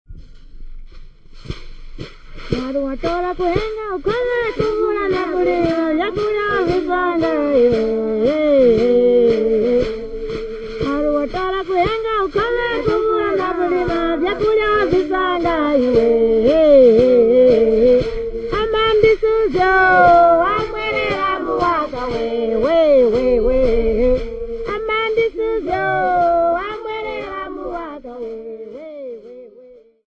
Group of Tumbuka/Henga women
Folk music--Africa
Field recordings
Africa Zambia Mulfulira f-za
A pounding song accompanied by raft rattles.